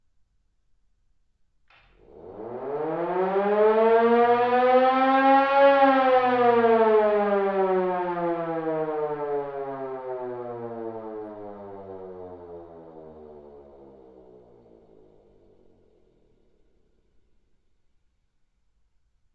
siren short low
描述：Siren pitched down.
标签： airraid siren
声道立体声